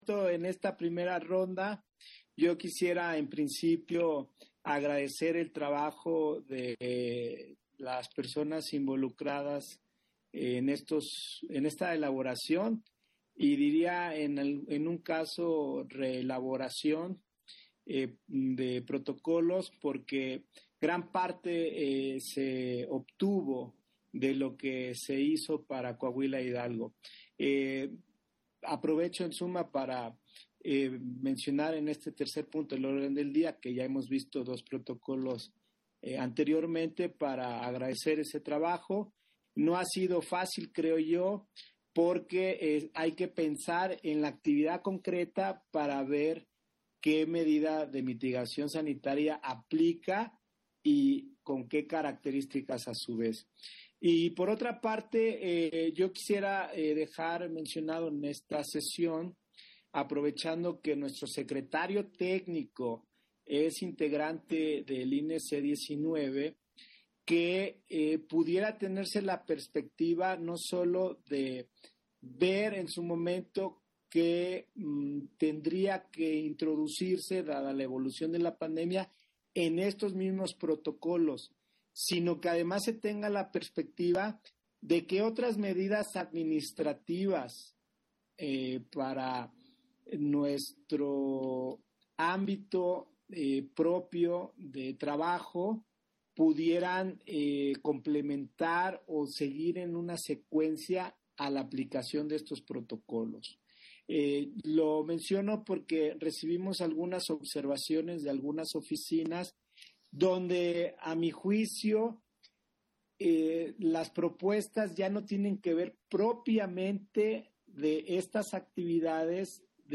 Intervención de José Roberto Ruiz, en el punto 3 de la sesión ordinaria de la Comisión de Capacitación Electoral, en el cual se aprueba el protocolo sanitario para la operación de casilla única en la Jornada Electoral